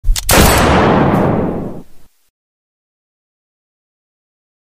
Sound Effect Bunyi Pistol
Kategori: Efek suara
Keterangan: Efek suara bunyi pistol adalah efek suara yang meniru suara tembakan senjata api, sering digunakan dalam video meme dan konten lucu untuk memberikan kesan dramatis atau komedi.
sound-effect-bunyi-pistol-id-www_tiengdong_com.mp3